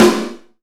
SNARE 008.wav